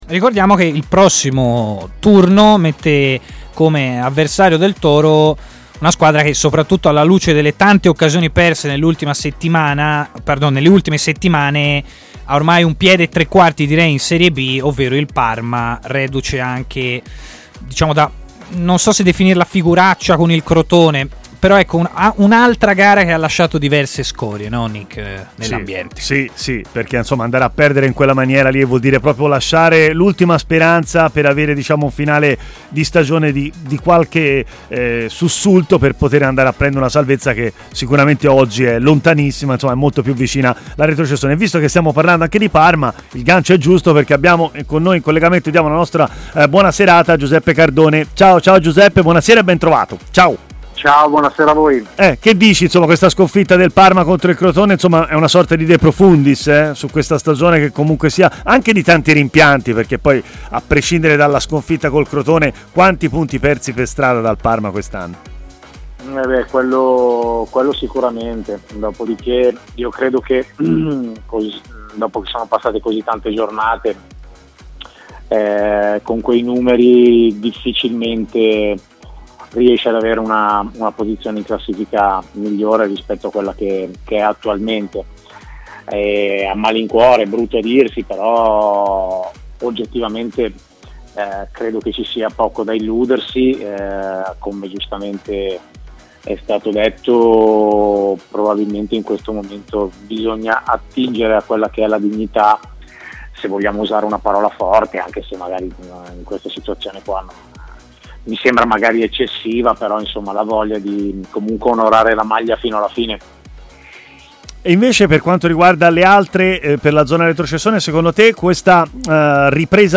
L'ex difensore Giuseppe Cardone ha parlato in diretta a TMW Radio